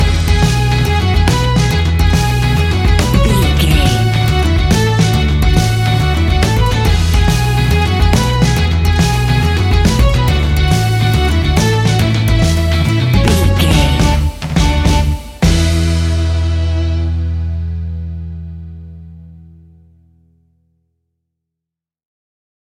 Ionian/Major
instrumentals
acoustic guitar
mandolin
double bass
accordion